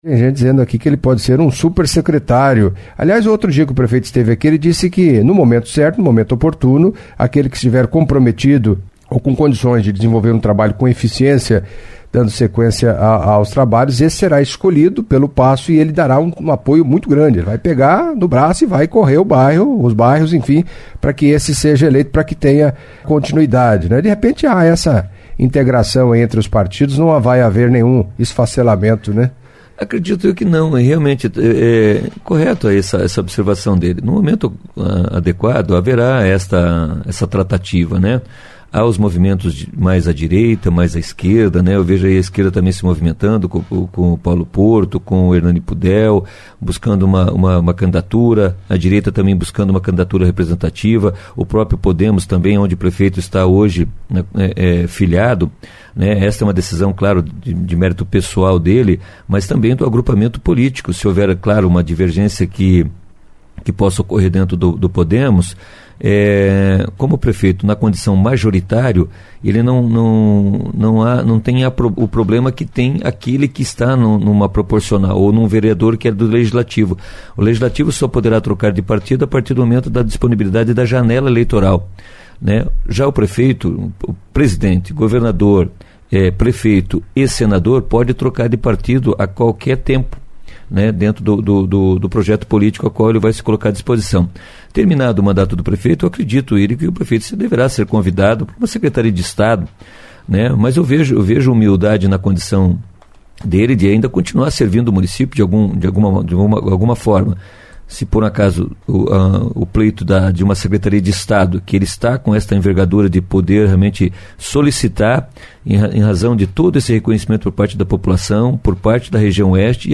Em entrevista à CBN Cascavel nesta terça-feira (12)